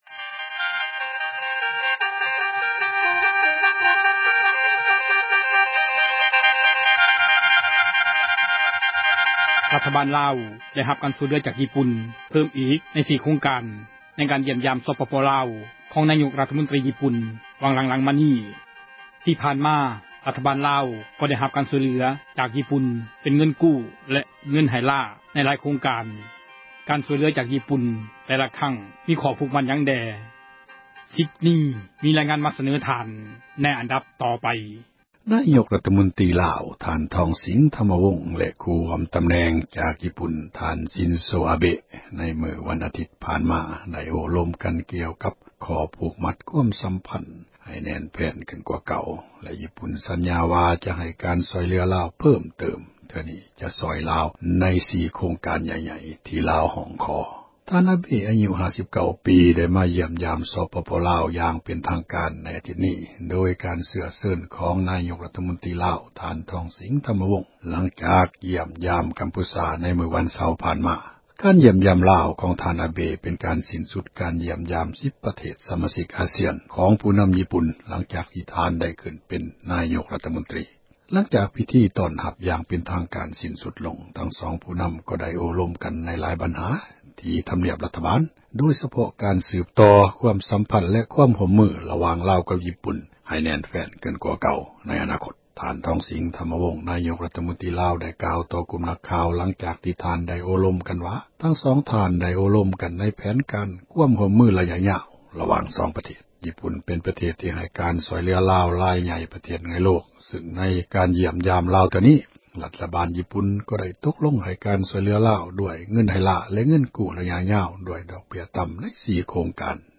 ດັ່ງທ່ານ ທອງສິງ ທັມມະວົງ ນາຍົກຣັຖມົນຕຣີລາວກ່າວກ່ຽວກັບ ການຢ້ຽມຢາມຂອງຜູ້ນຳຍິ່ປຸ່ນວ່າ: